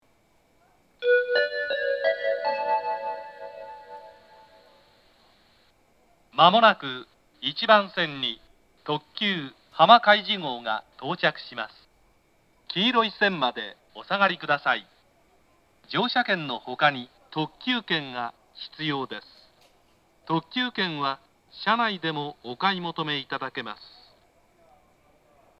東海道型(男性)
接近放送